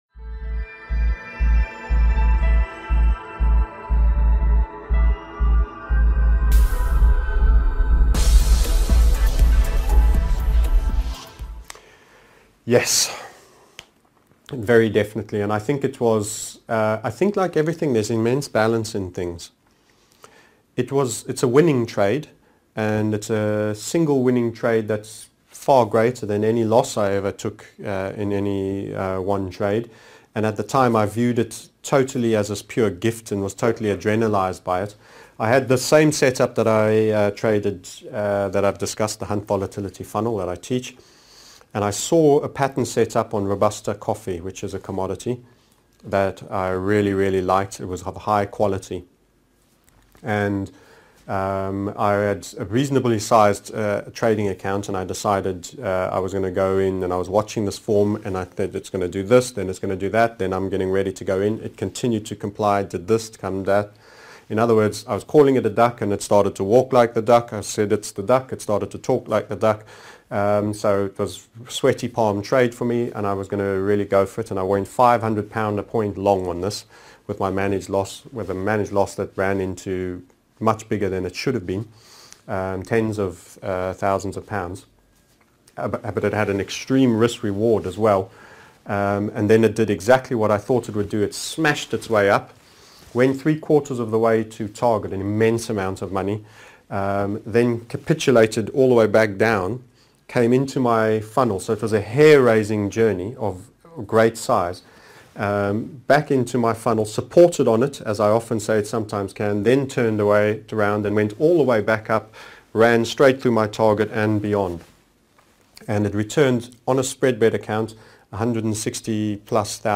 23 Which trade had the most profound effect on you as a trader TMS Interviewed Series 23 of 32